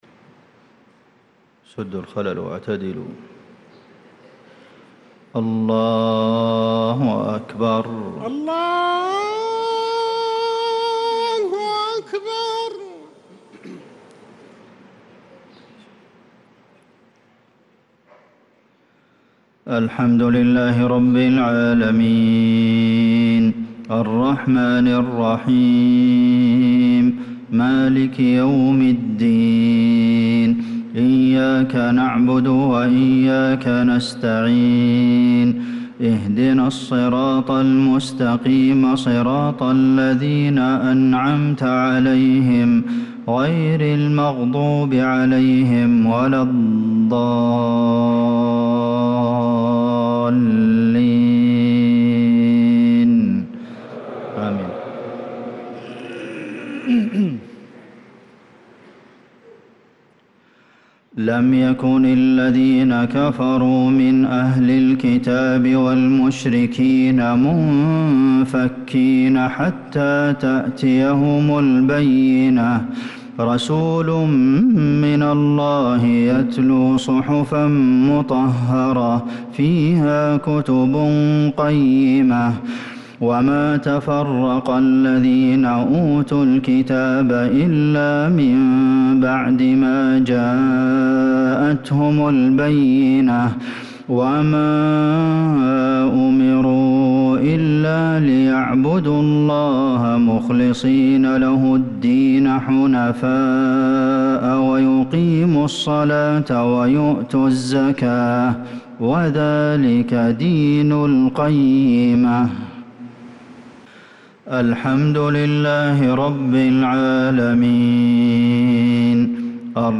صلاة المغرب للقارئ عبدالمحسن القاسم 8 صفر 1446 هـ
تِلَاوَات الْحَرَمَيْن .